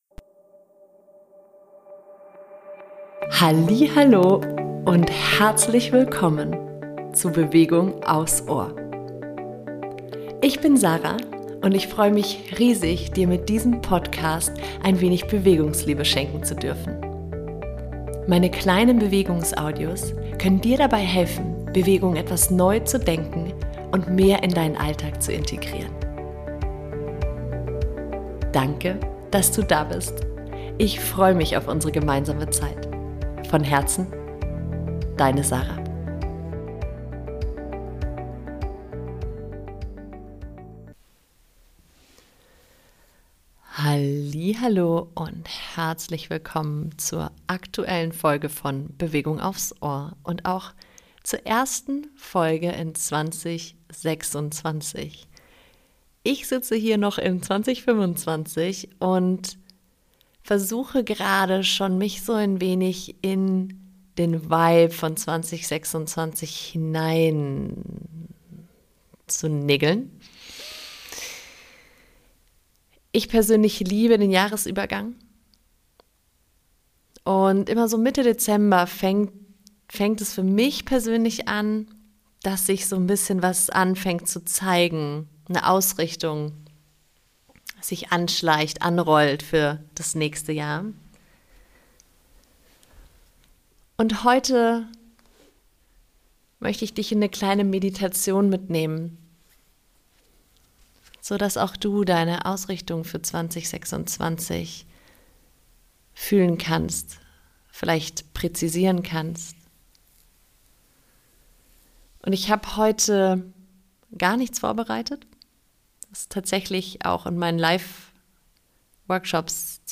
Beschreibung vor 3 Monaten In dieser Folge lade ich dich ein, gemeinsam mit mir eine Meditation zu erleben, die dir hilft, deine persönliche Ausrichtung für das Jahr 2026 zu entdecken.